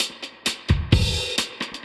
Index of /musicradar/dub-designer-samples/130bpm/Beats
DD_BeatB_130-02.wav